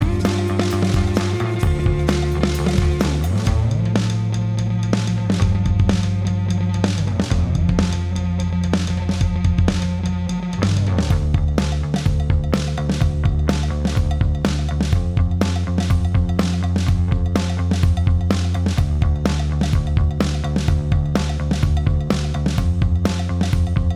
No Lead Guitar Rock 3:25 Buy £1.50